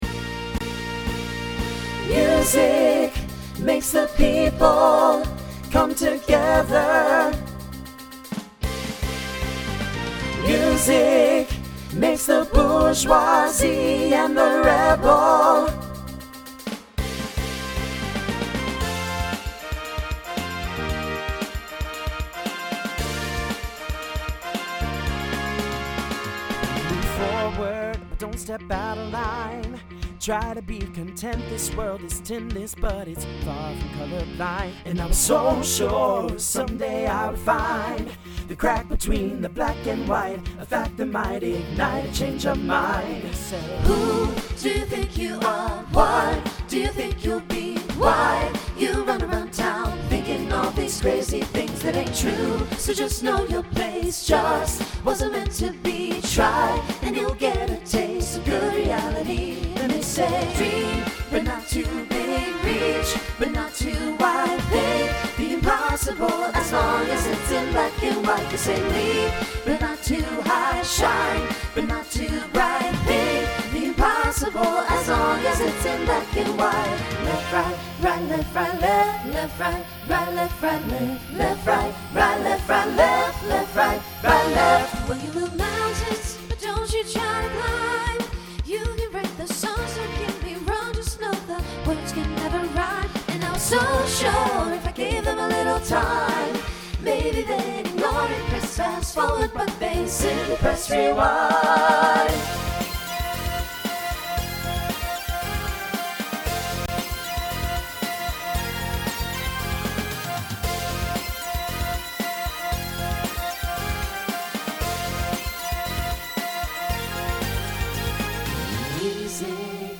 Voicing SATB Instrumental combo Genre Pop/Dance
2010s Show Function Mid-tempo